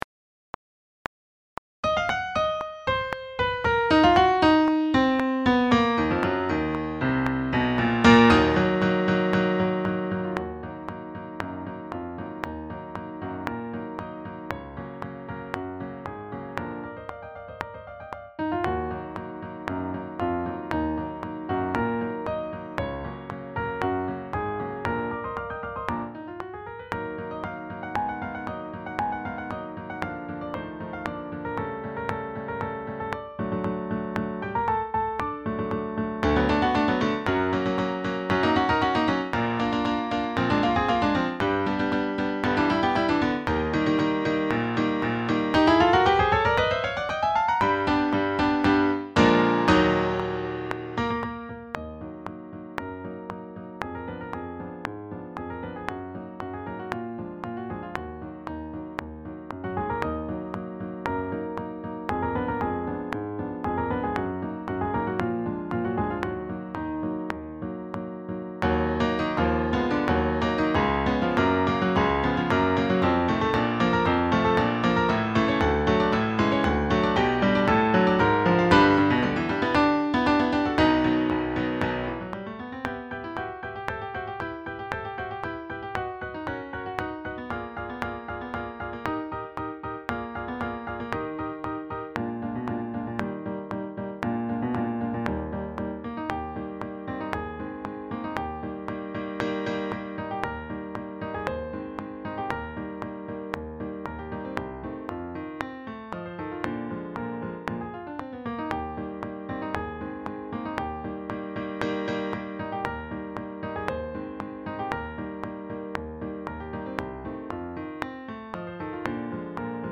Sax Choir
Robrecht was well known as a composer of dance tunes, but this ''Symphonic Foxtrot'' was made famous in the UK by the Blackpool Tower Ballroom Band.
It includes parts for 15 saxes from the tiny sopranissimo (soprillo) to the mighty contrabass with the oft-neglected C Melody in the middle.
Backing track
169-4-samum-maximus-backing-track.mp3